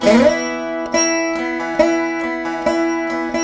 138  VEENA.wav